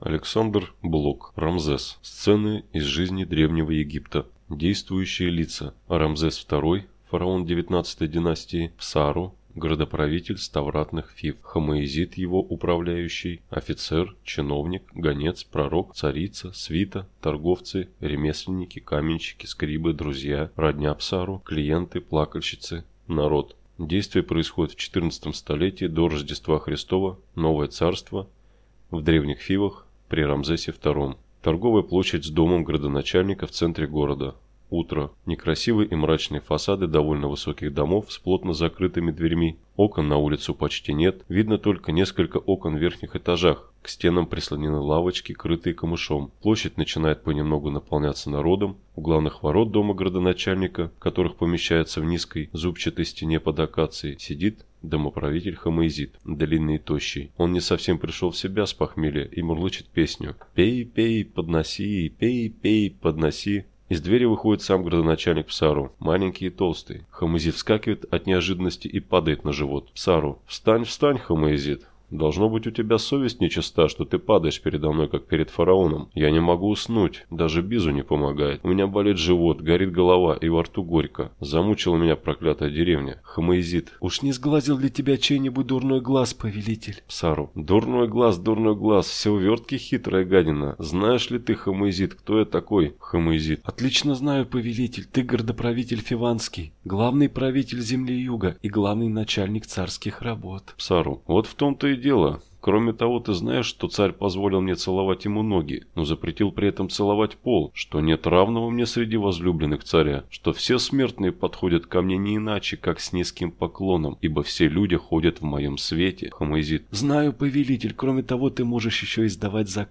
Аудиокнига Рамзес | Библиотека аудиокниг